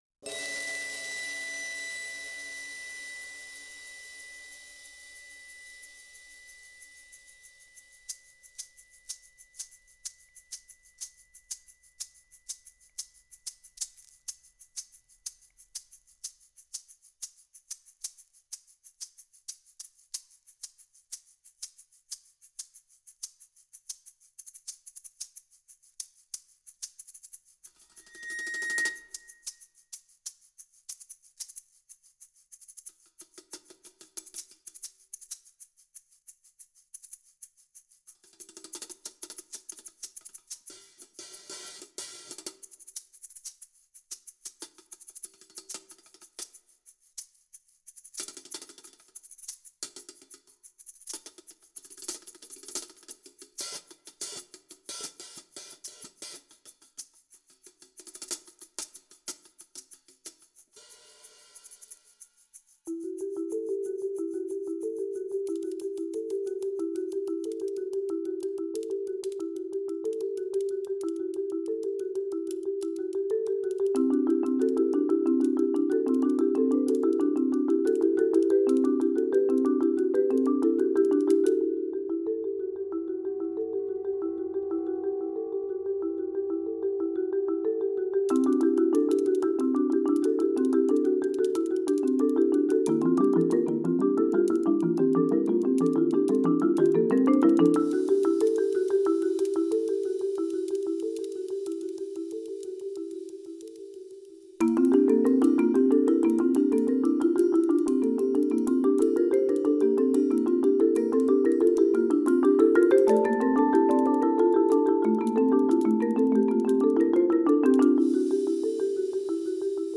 Voicing: Percussion Sextet